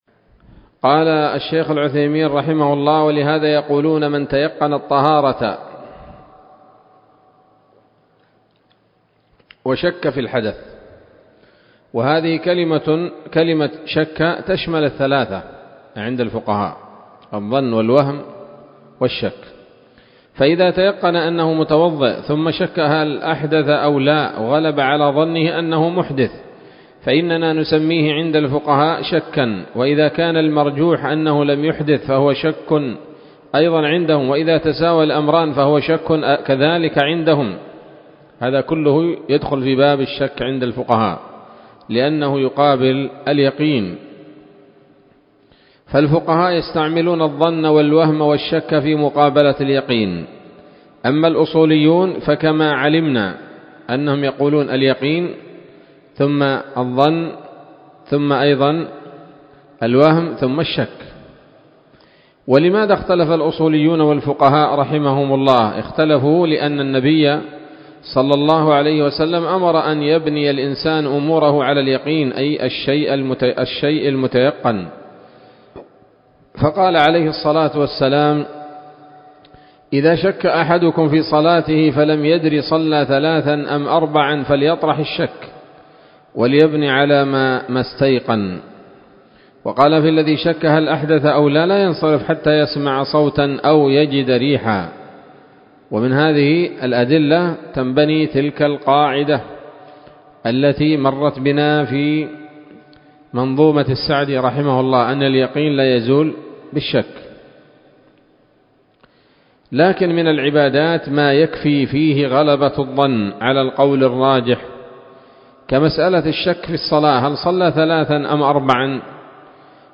الدرس السادس والعشرون من شرح نظم الورقات للعلامة العثيمين رحمه الله تعالى